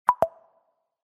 Spotify Previous Song Sound Effect Free Download